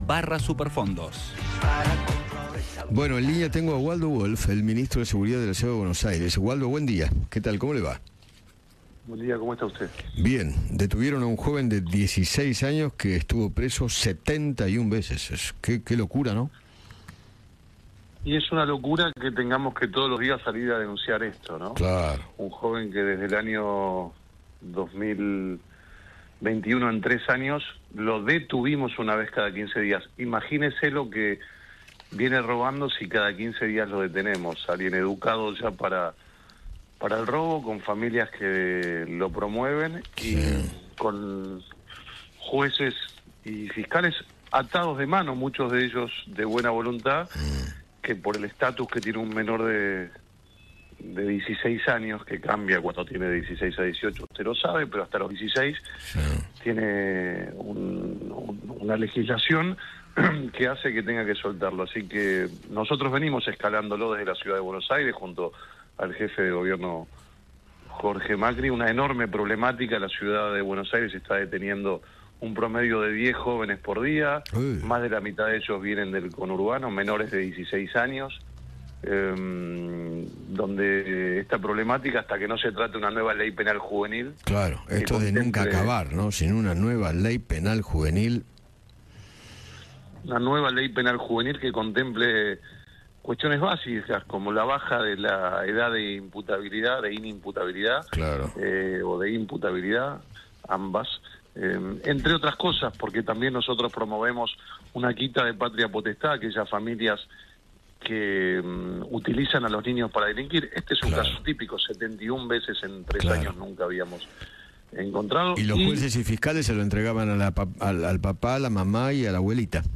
El ministro de Seguridad porteño, Waldo Wolff, habló con Eduardo Feinmann sobre la polémica que se generó luego de la detención de un menor que ya cometió 71 reincidencias.